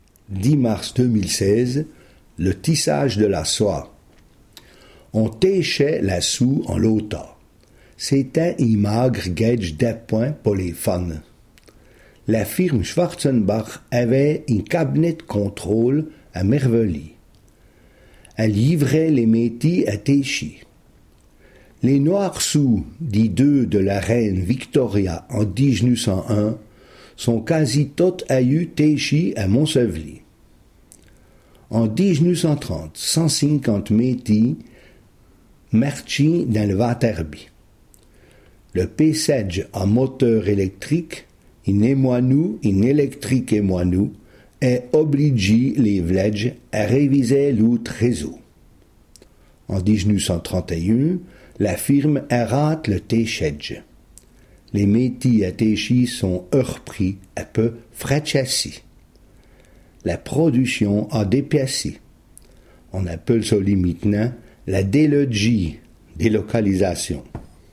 Sommaire Ecouter le r�sum� en patois Panneau complet, consulter ou Situation ; coordonn�es (...)